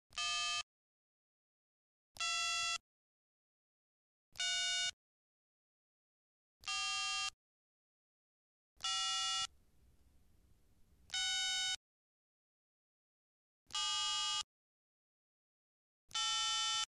На этой странице собраны звуки набора номера в телефоне — от винтажных импульсных гудков до современных тональных сигналов.
Звук нажатия цифр при наборе номера на мобильном телефоне